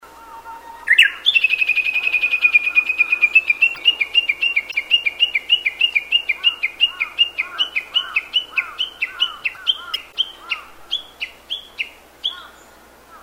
♬ ウグイス飛来。クリックすると谷渡り･･ ･ (^^♪ 【相談内別情況】 次のグラフは代表的な解決内容をマニュアル化、関連資料表示したものを10分野に分類しパレート図にしたものです。
uguisu.mp3